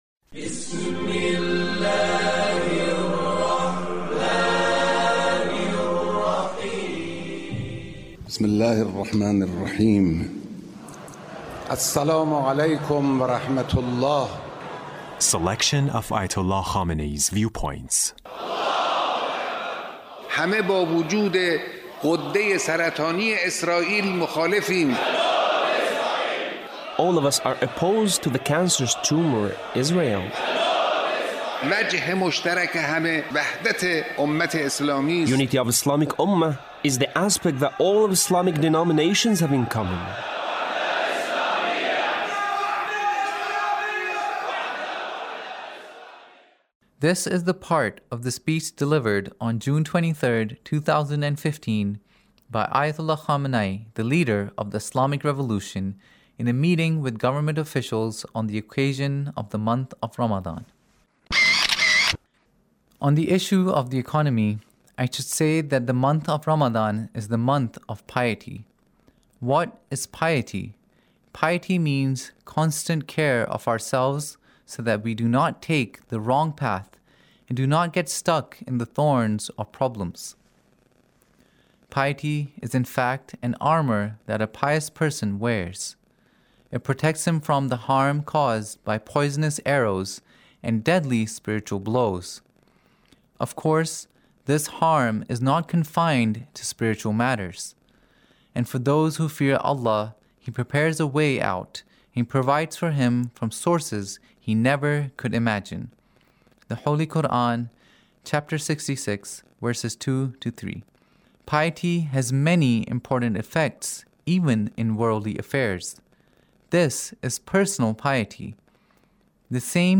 Leader's Speech On The Month of Ramadhan in a Meeting with the Government Officials